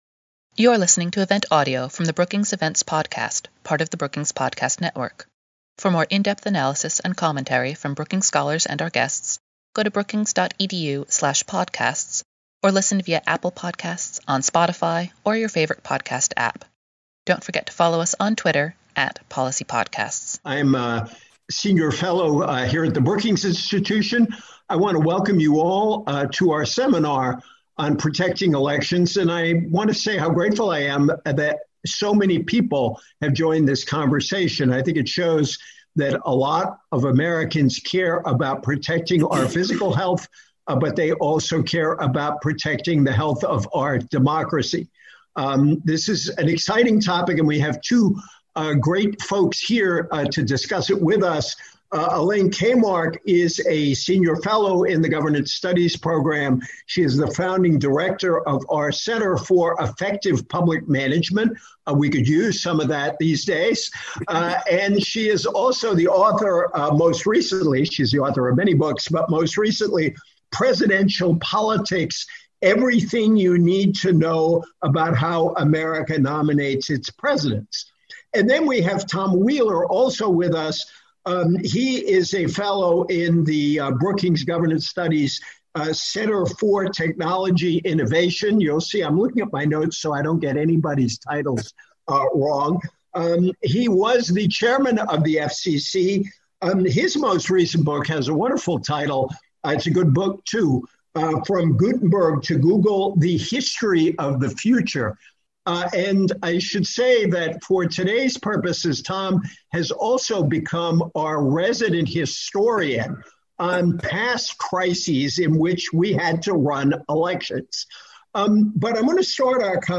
On April 14, Governance Studies at Brookings hosted a webinar discussion focusing on what steps policymakers, election officials, and political organizations can take to protect the integrity of elections during the COVID-19 pandemic and other national emergencies.